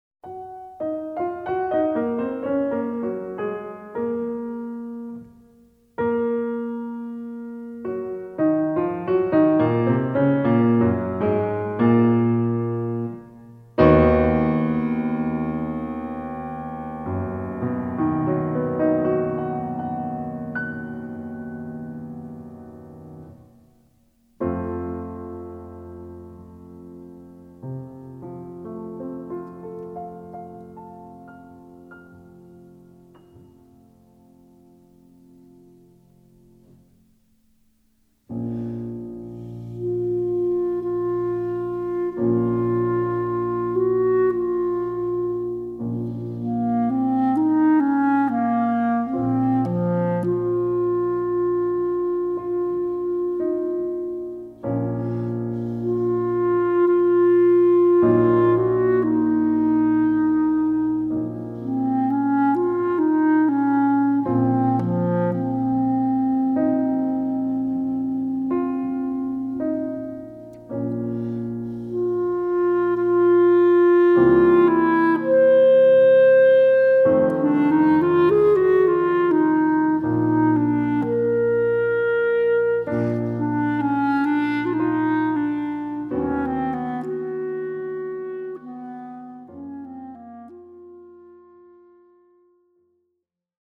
Im Norden im Suden Andante con moto